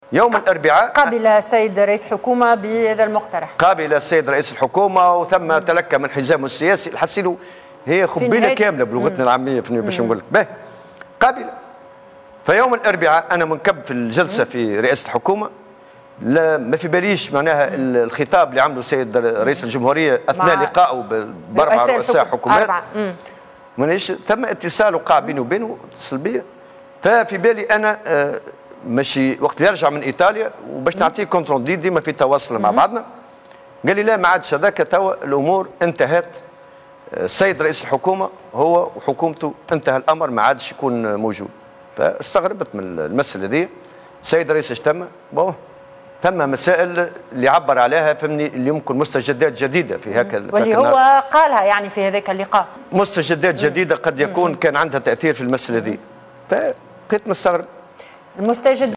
وقال الطبوبي في حوار مع قناة الحوار التونسي، الجمعة، إنه أبلغ هشام المشيشي رئيس الحكومة بهذا الطلب ووافق عليه رغم تلكؤ حزامه السياسي، إلا أن رئيس الجمهورية فاجأ الجميع بخطابه الذي أكد فيه أنه لن يشارك في الحوار وأدان فيه الحوار الوطني الذي قاده الرباعي سابقاً.